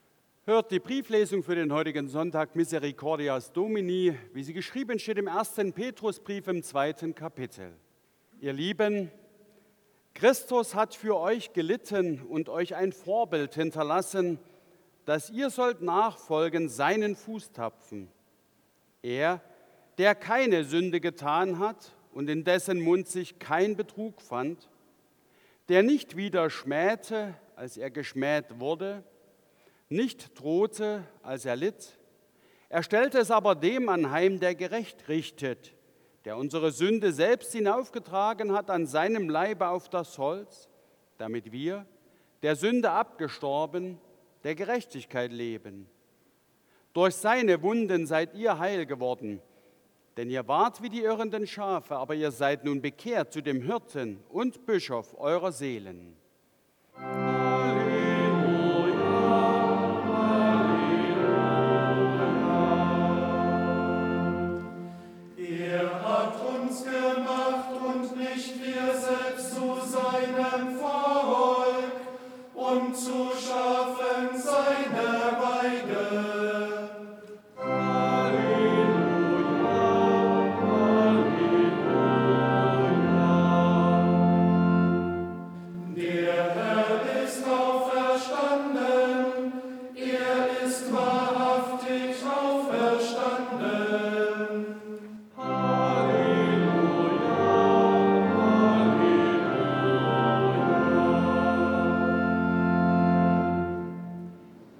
Brieflesung aus 1.Petrus 2,21-25 Ev.-Luth.
Audiomitschnitt unseres Gottesdienstes vom 2. Sonntag nach Ostern 2025.